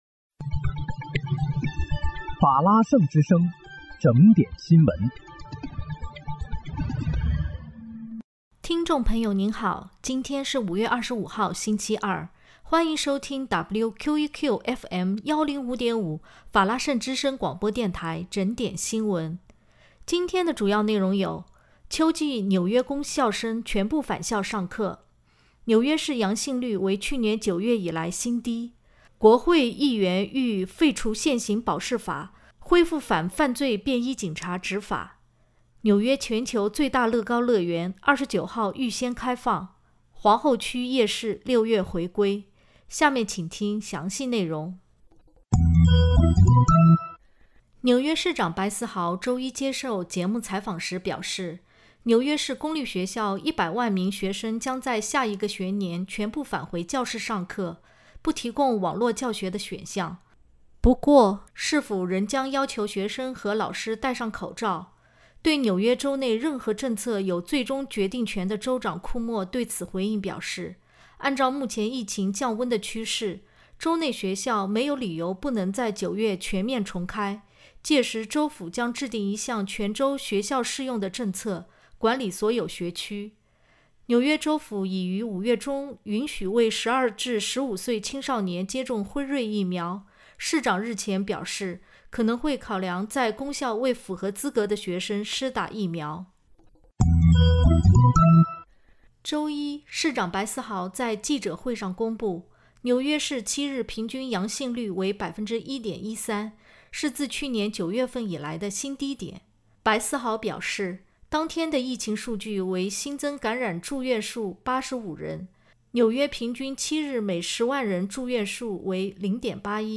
5月25日（星期二）纽约整点新闻
听众朋友您好！今天是5月25号，星期二，欢迎收听WQEQFM105.5法拉盛之声广播电台整点新闻。